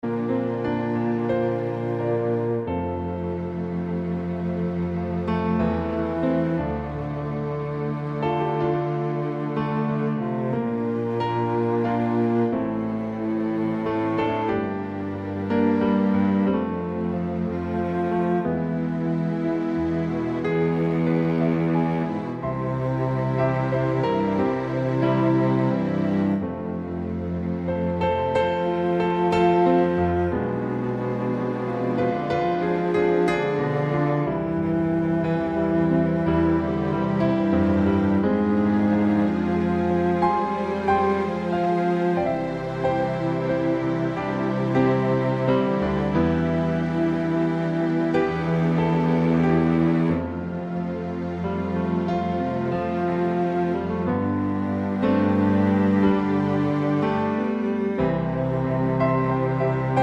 Male Standard Key